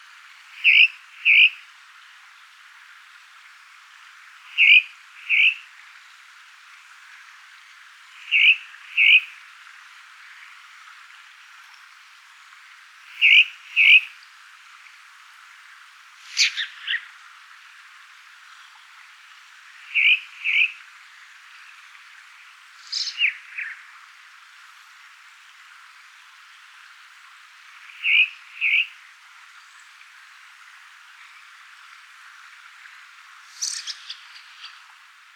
Звуки дрозда
Черный дрозд Turdus merula